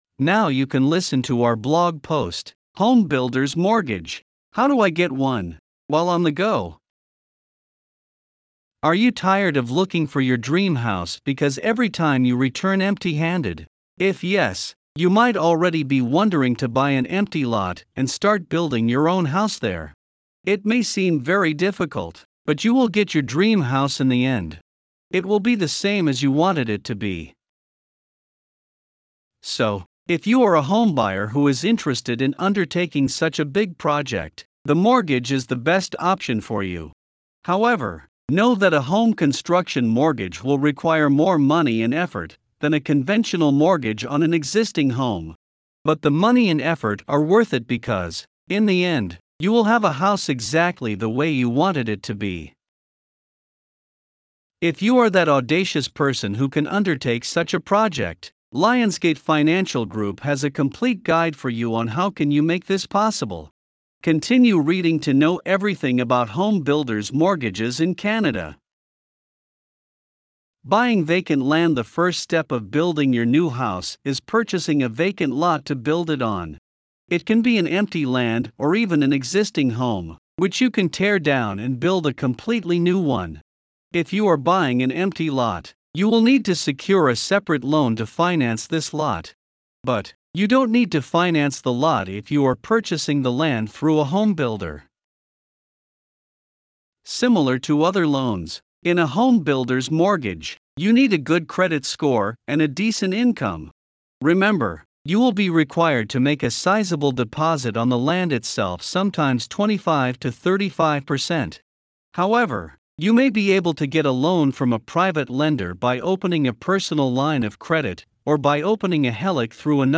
Voiceovers-Voices-by-Listnr_9.mp3